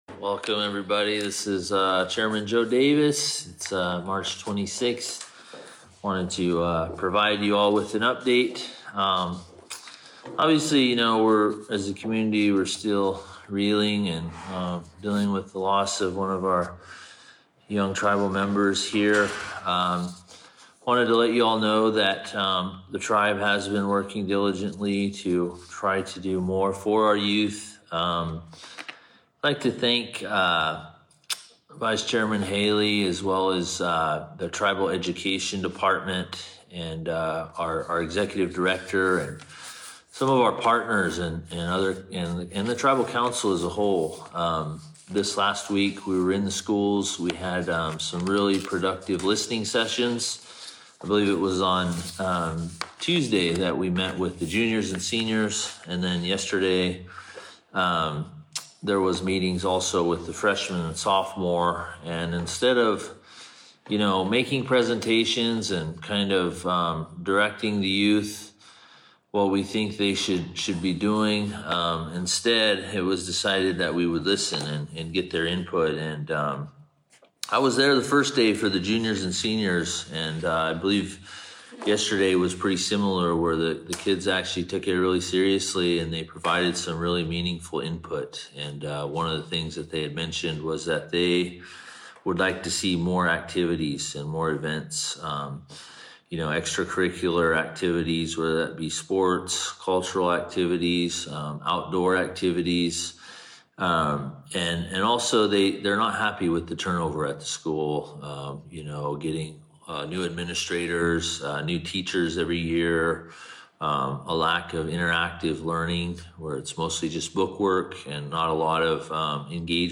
This is the audio recording of the Chairman’s Facebook live report of March 26, 2026.